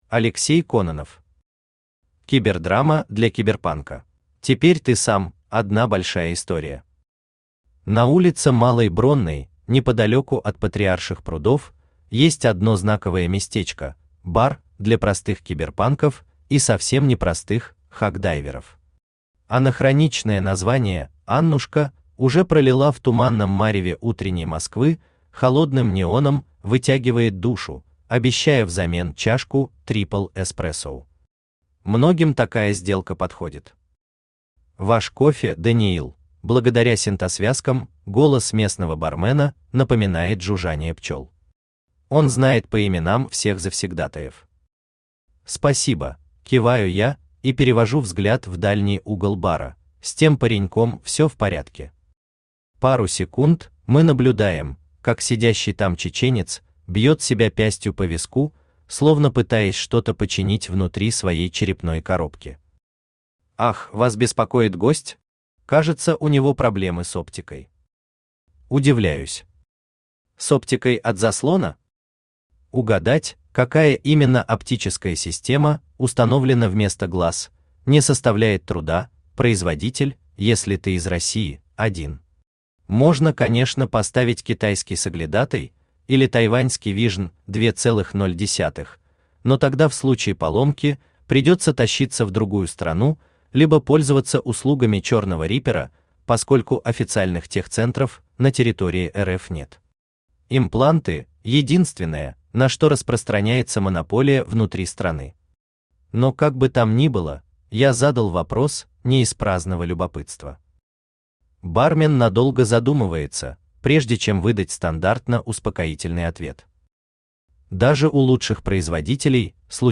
Аудиокнига Кибердрама для киберпанка | Библиотека аудиокниг
Aудиокнига Кибердрама для киберпанка Автор Алексей Кононов Читает аудиокнигу Авточтец ЛитРес.